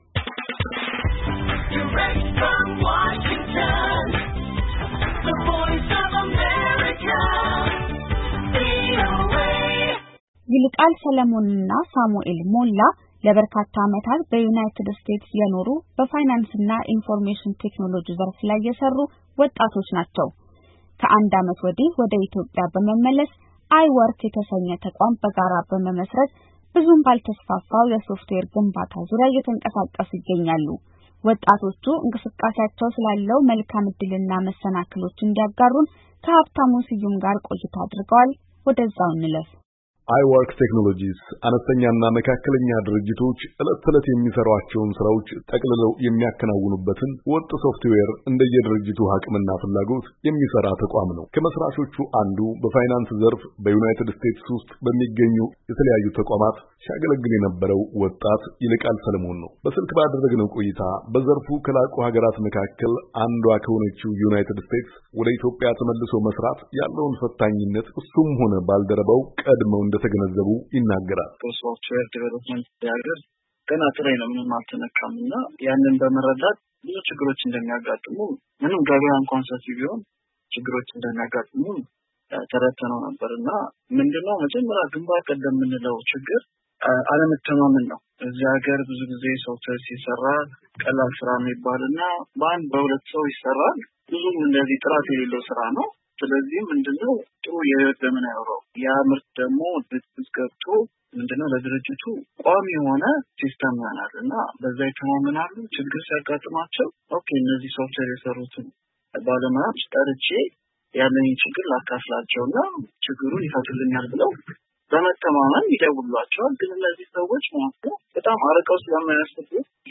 ከወጣቶቹ ጋር አጭር ቆይታ አድርጓል።